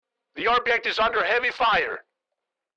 Download Free Military Radio Sound Effects | Gfx Sounds
Military Radio Sound Effects designed to bring authentic Military Radio Communications to your projects.
Military-radio-voice-the-objective-is-under-heavy-fire.mp3